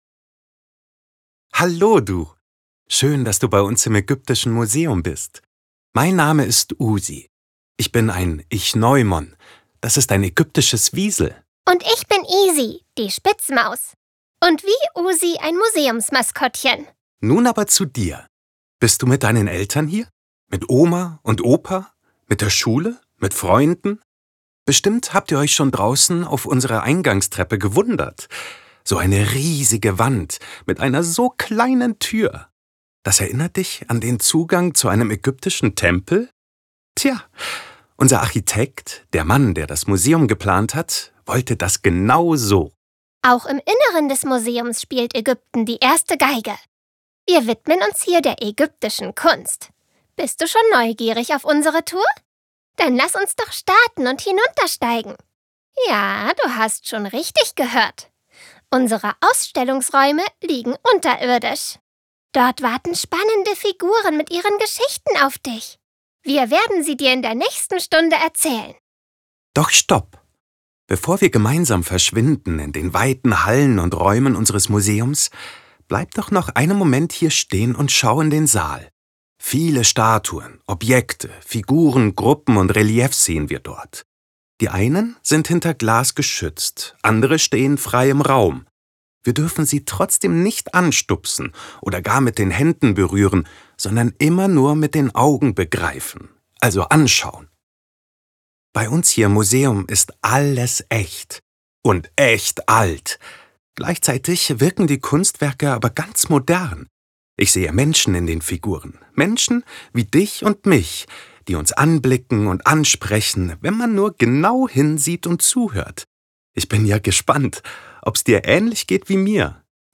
00_VR_AegyptischesMuseum_Multimediaguide_Begruessung.wav